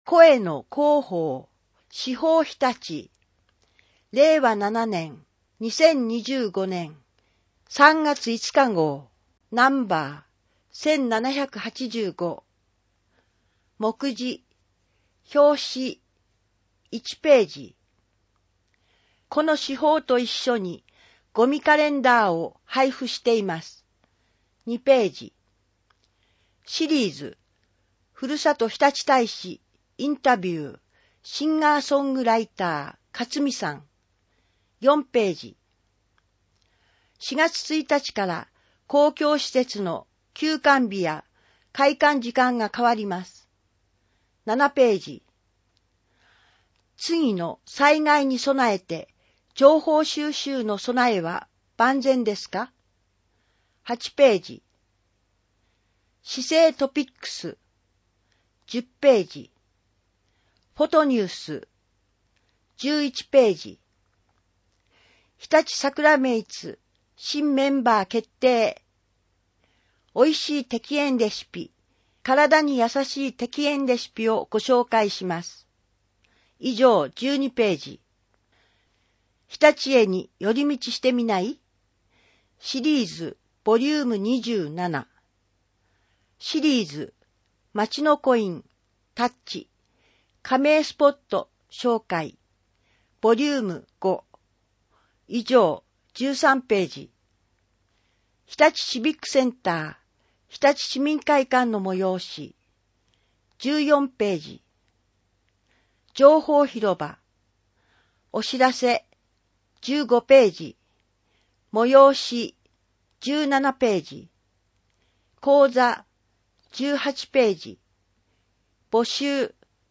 声の市報を読みあげます。